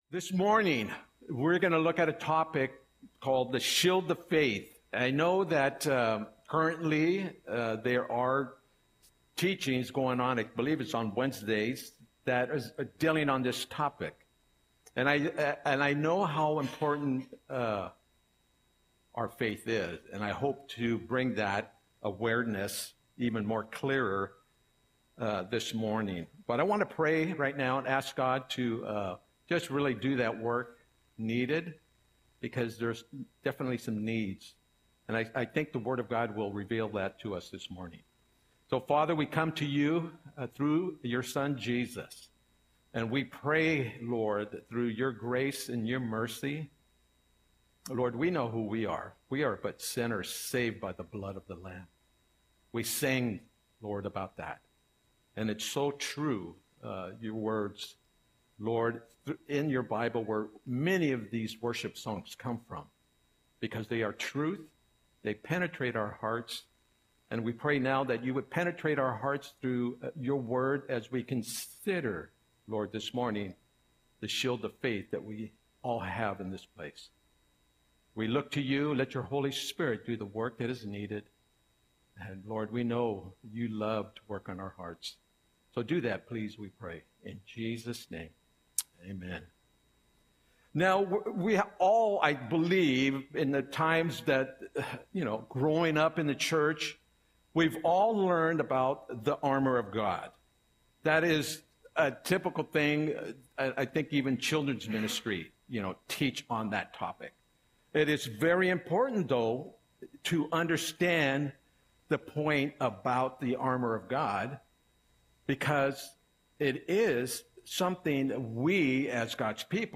Audio Sermon - March 30, 2025